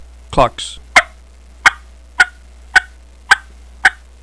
Listen to 4 seconds of clucks
primosraspydoublehookclucks4.wav